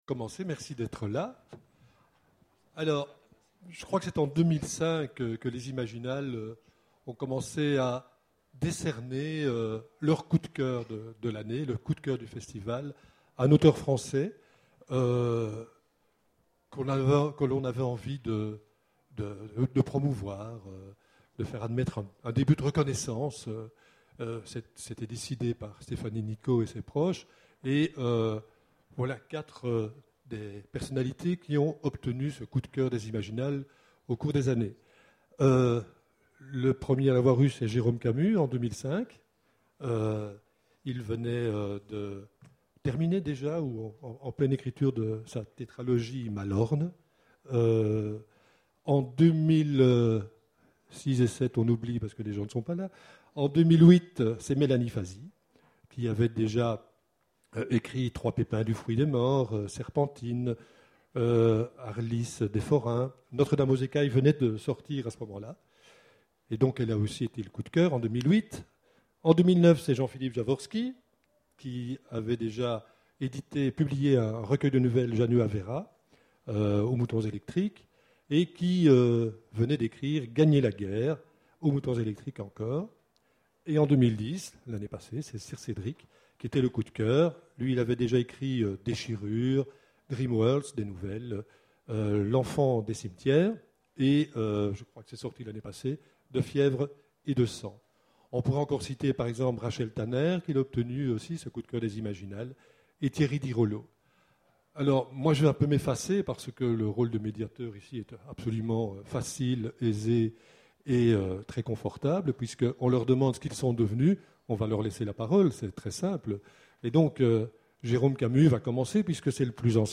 Voici l'enregistrement de la Conférence sur les anciens coups de coeur des Imaginales, que sont-ils devenus ?.